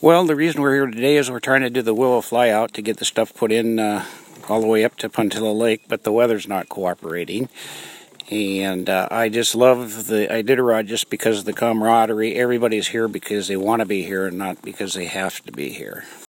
Temperature: 10F / OUTDOORS